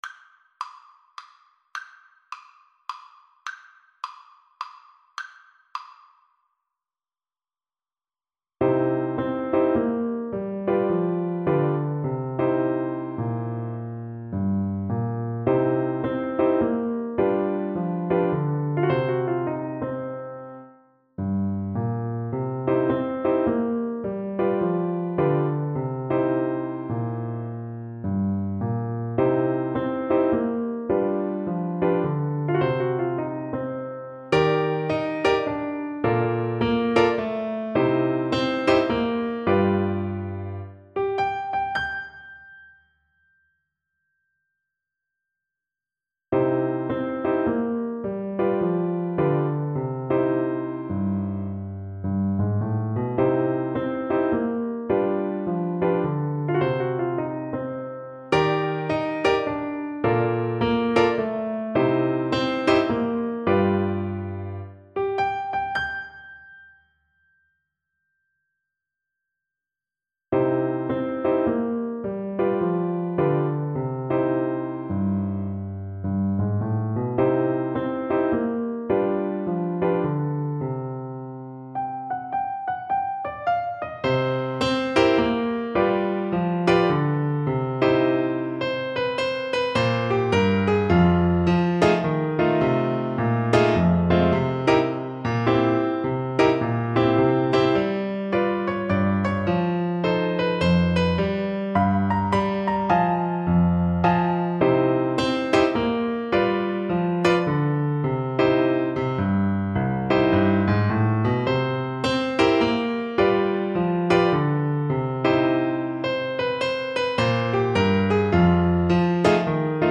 3/4 (View more 3/4 Music)
=140 Fast swing